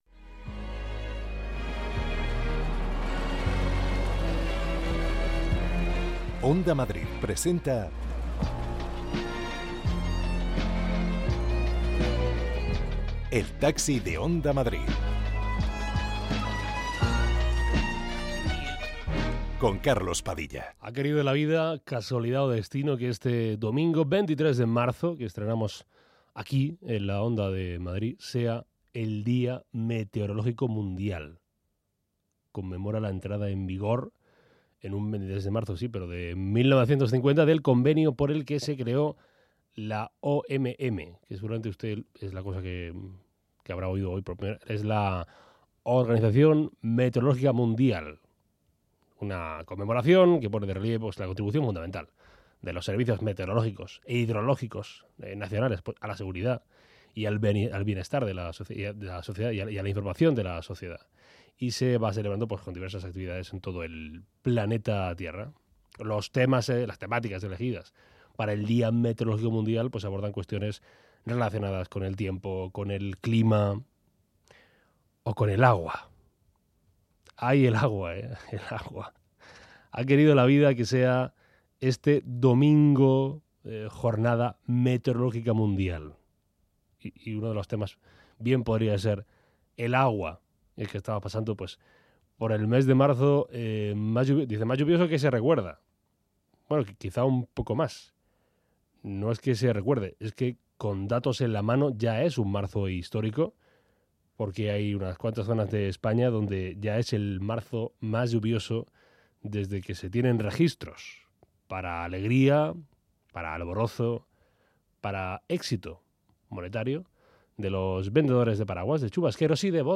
Conversaciones para escapar del ruido.